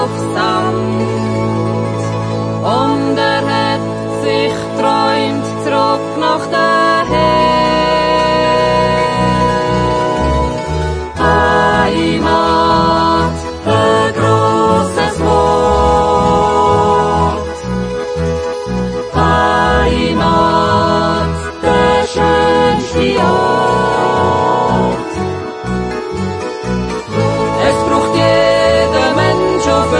Jodellieder, Naturjodel, Ratzliedli